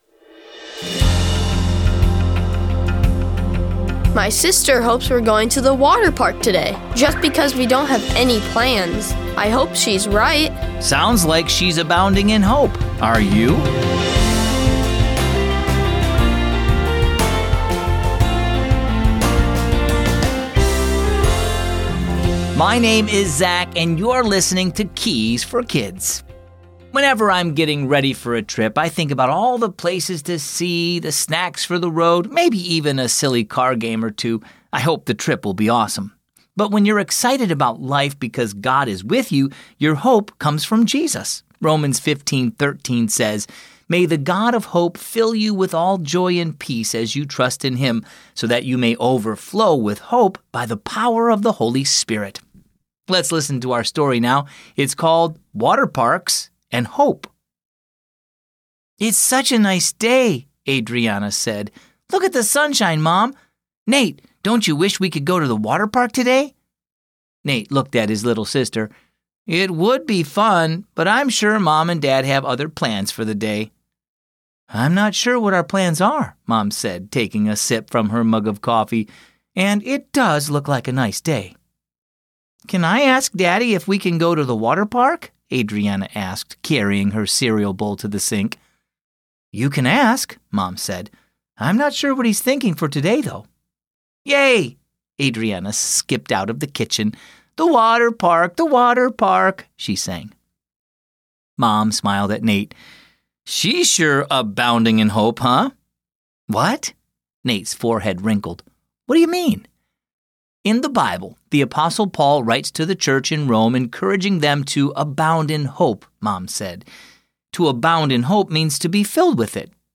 Keys for Kids is a daily storytelling show based on the Keys for Kids children's devotional.
this podcast combines Scripture readings with captivating stories that illustrate essential Biblical principles.